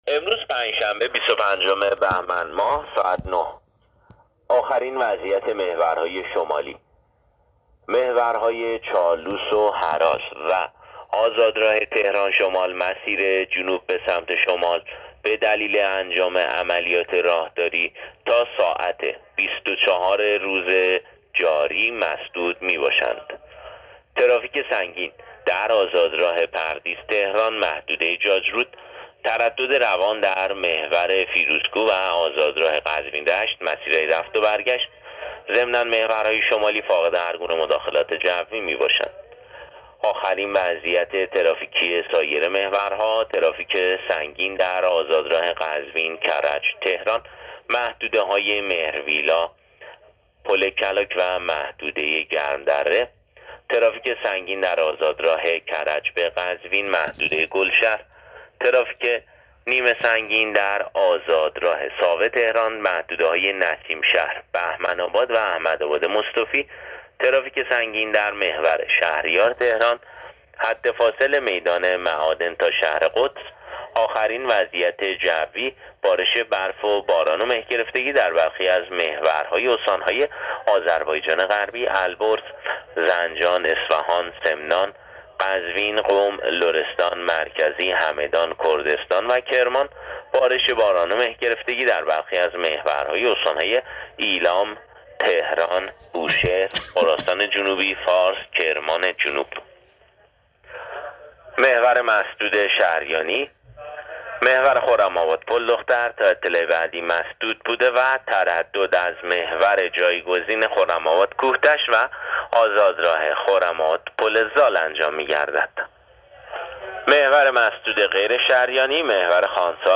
گزارش رادیو اینترنتی از آخرین وضعیت ترافیکی جاده‌ها ساعت ۹ بیست و پنجم بهمن؛